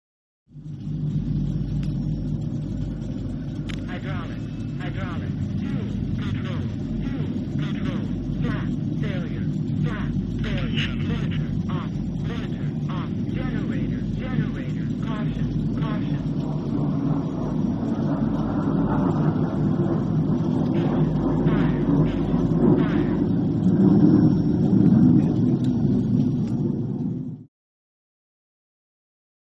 Betty During Taxi; Jet Taxi, Squeak And Clatter Of Wheels, Interior Perspective Engine Growl, Various Computerized Warning Messages With Radio Squelching. Jet.